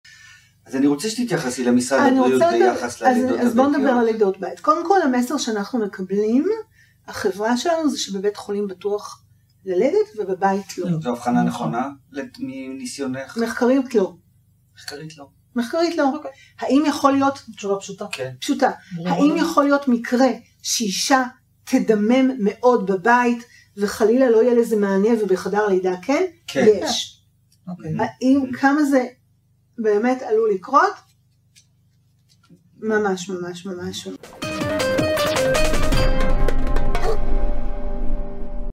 על לידות ביתיות מתוך הראיון המלא